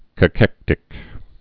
(kə-kĕktĭk)